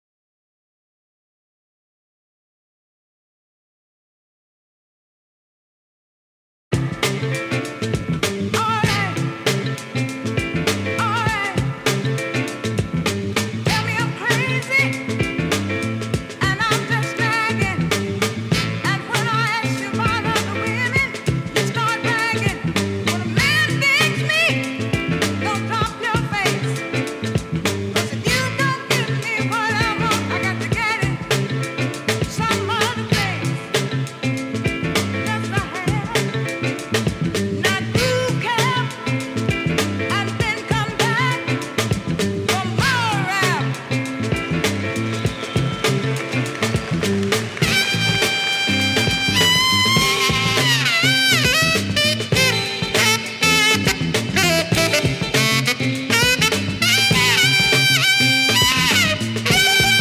classic funky soul